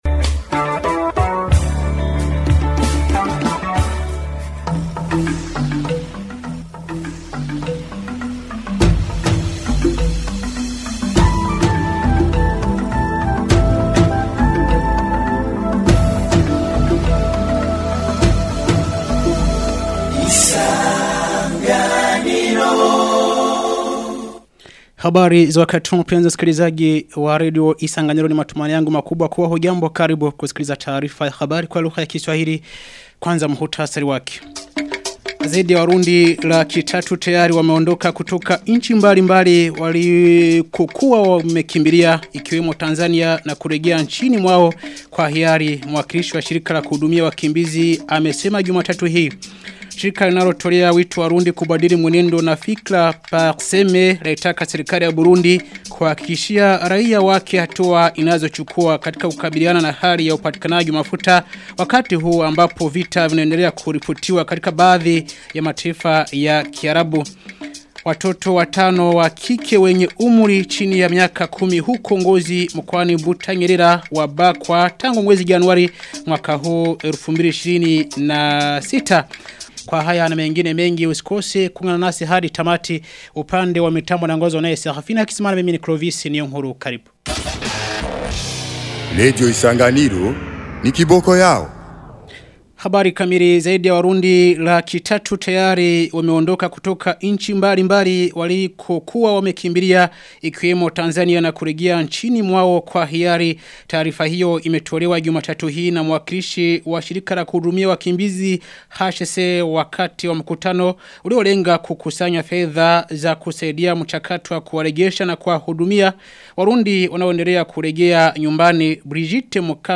Taarifa ya habari ya tarehe 2 Marchi 2026